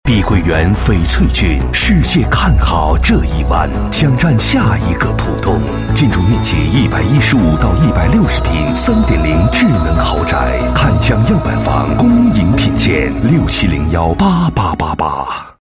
标签： 大气
配音风格： 稳重 大气 欢快 激情